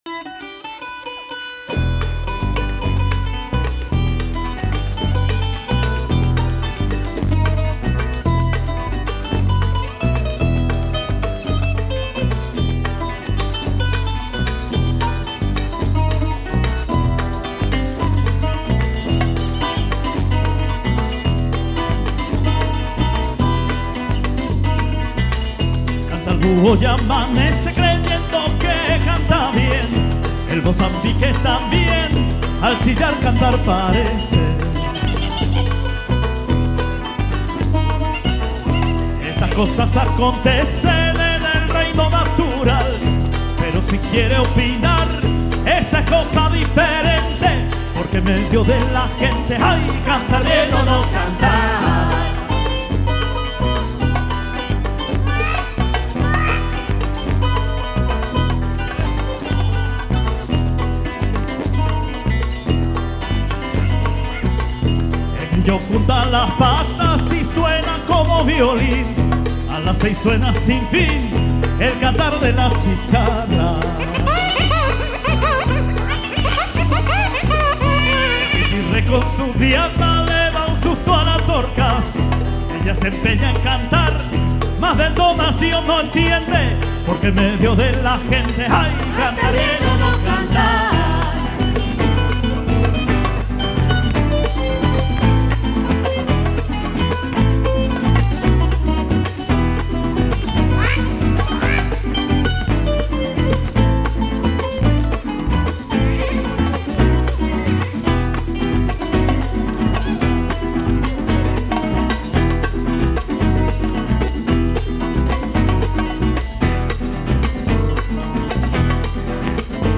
voce, chitarra, tiple, charango
sax tenore e soprano. flauti
Registrato a Milano - CSOA Leoncavallo il 7 marzo 2000